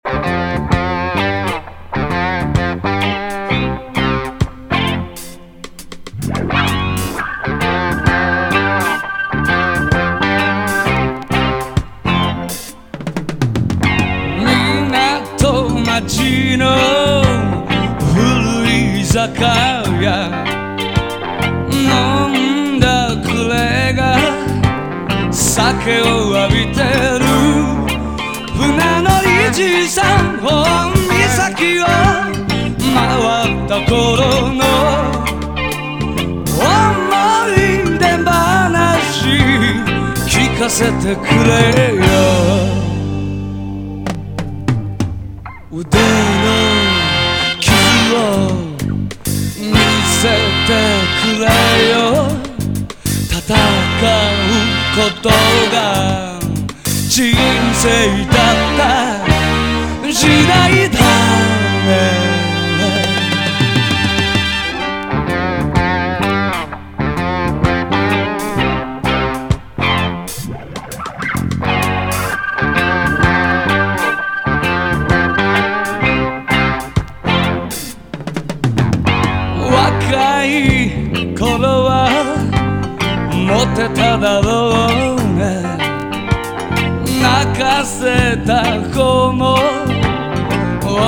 ELECTONE LOUNGE / JAPANESE GROOVE / JAPANESE LOUNGE
独特な和モノ・エレクトーン・グルーヴ！